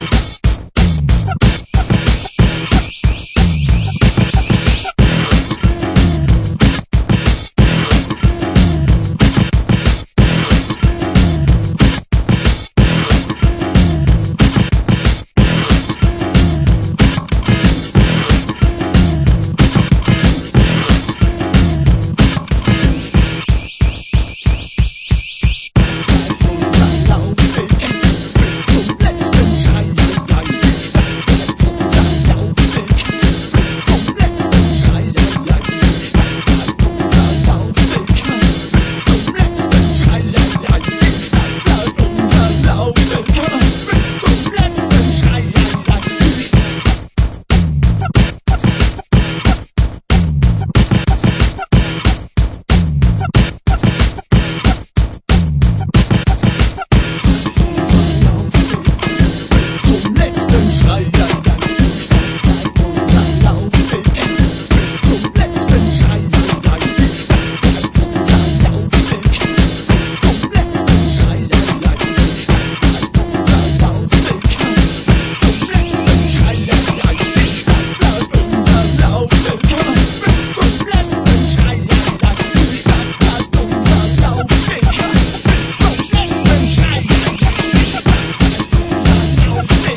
4 new Clubtracks/ remixes